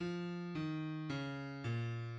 {\clef bass \tempo 4 = 110 \key aes \major f ees des bes, }\midi{}